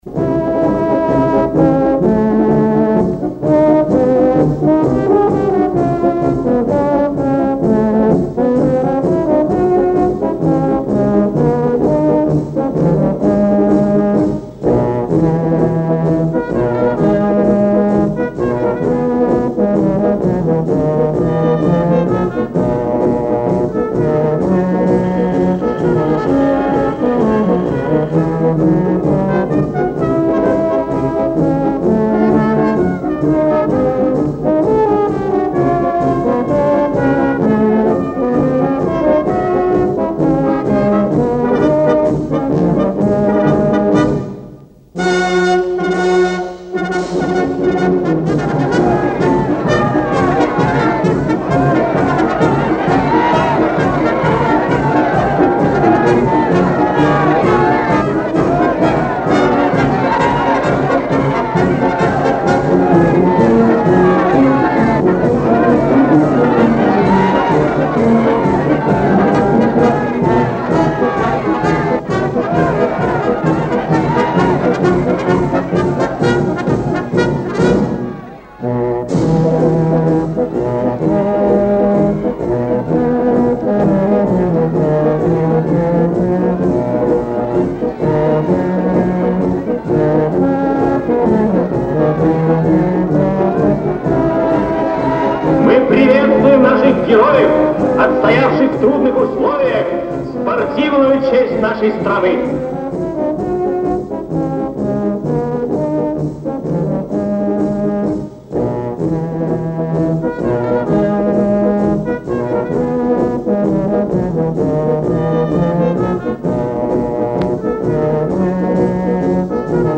Финал фильма-марш и последняя тема